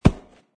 grass2.mp3